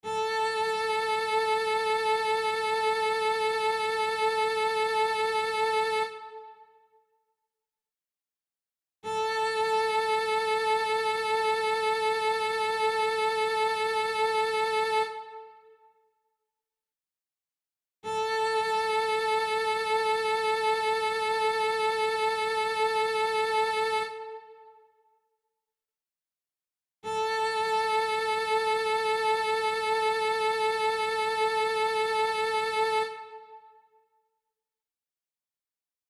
Listen to the note ... it's higher than the first note, sing it loudly!